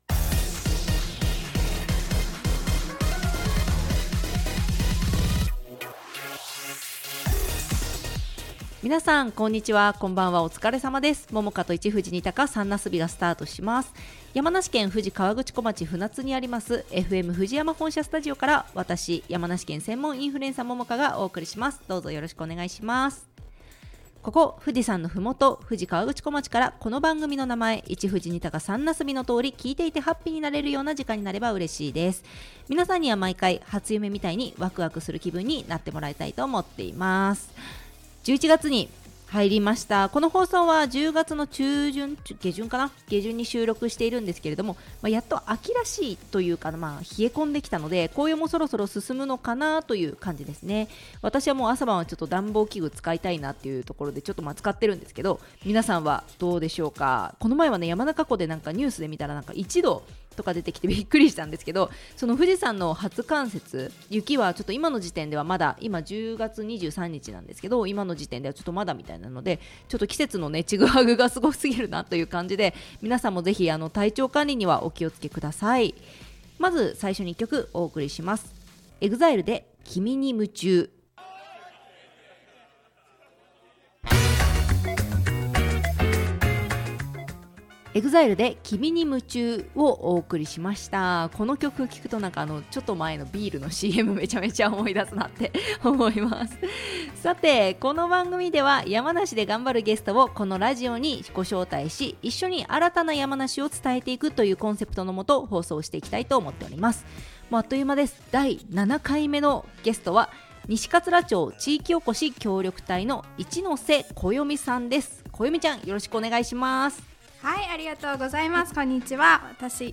（ネット配信の為楽曲はカットしています）